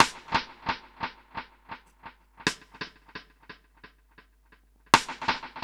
Index of /musicradar/dub-drums-samples/85bpm
Db_DrumsA_SnrEcho_85_01.wav